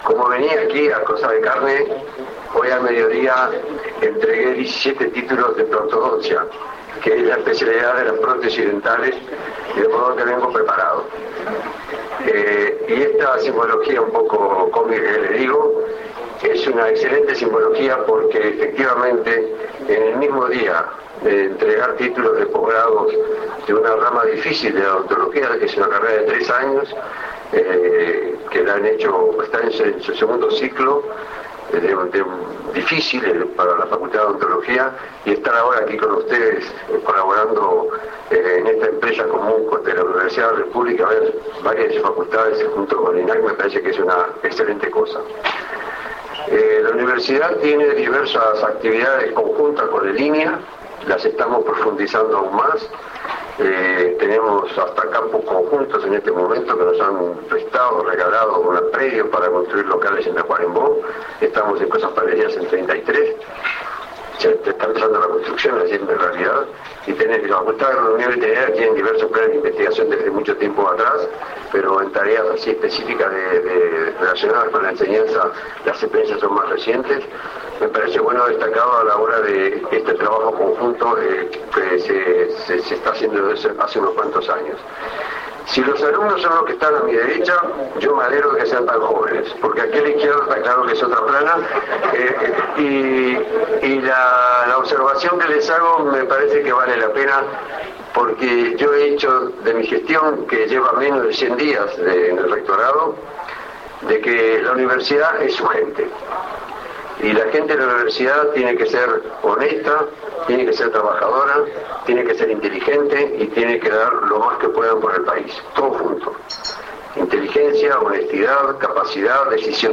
El Rector de la Universidad de la República Roberto Markarian subrayó la importancia de tener jóvenes actualizados, comprometidos con el país, trabajando con honestidad, inteligencia y capacidad.